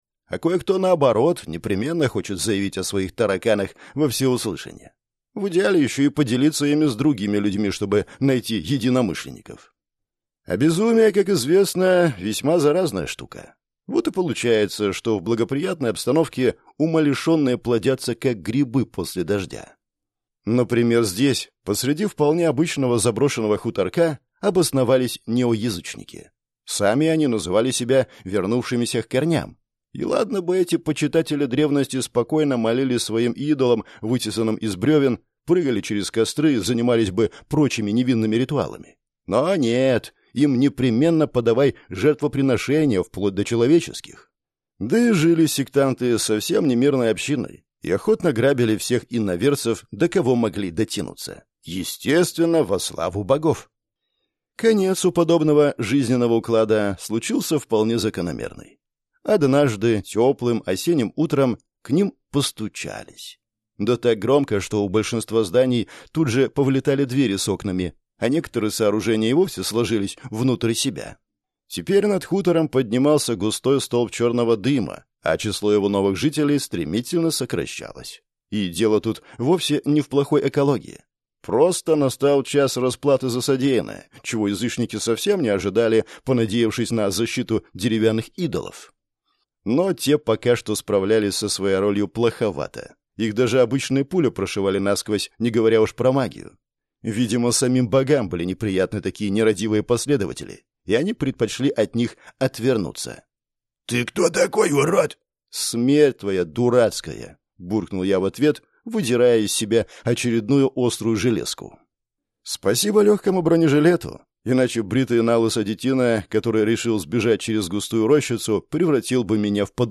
Аудиокнига Волшебство не вызывает привыкания. Книга 4 | Библиотека аудиокниг